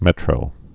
(mĕtrō) Informal